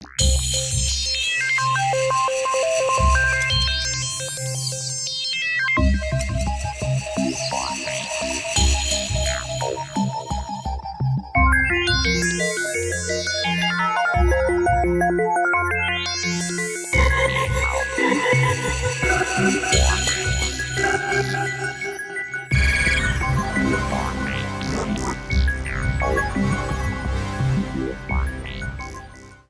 trimmed to 29.5 seconds and faded out the last two seconds